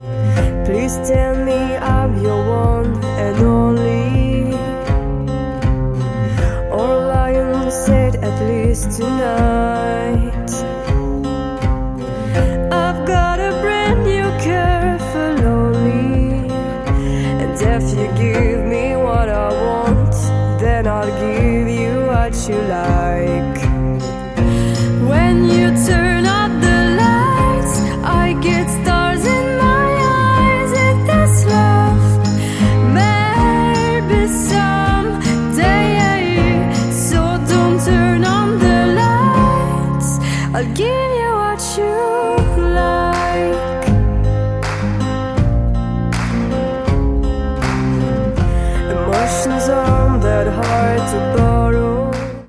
Après le studio ! ✨